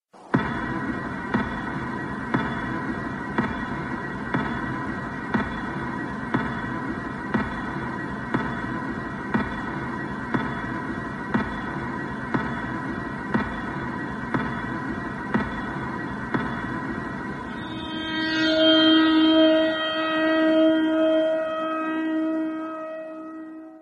hunger-games-countdown-clock_25386.mp3